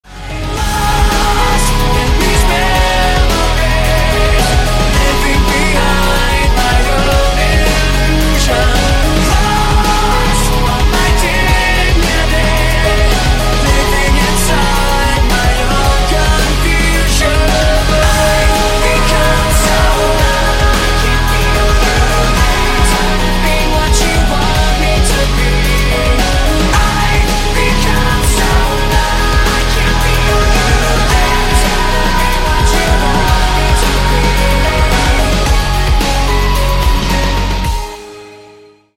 Рингтоны Альтернатива
Рок Металл Рингтоны